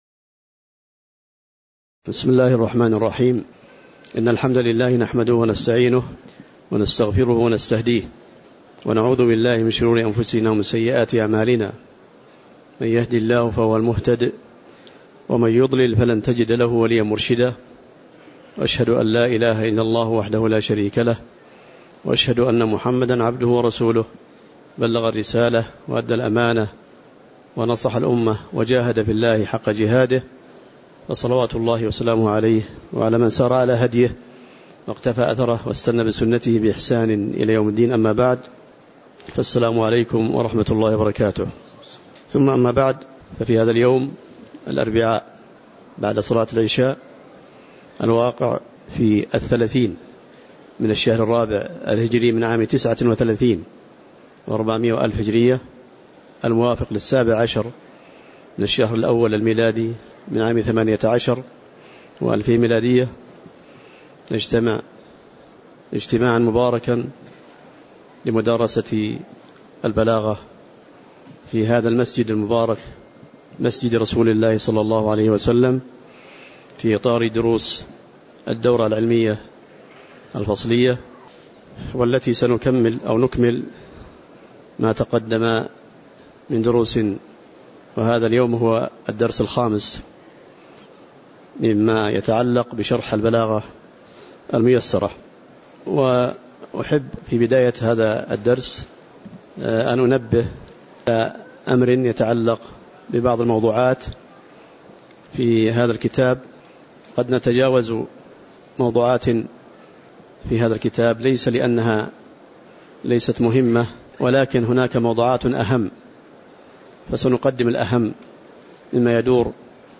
تاريخ النشر ٣٠ ربيع الثاني ١٤٣٩ هـ المكان: المسجد النبوي الشيخ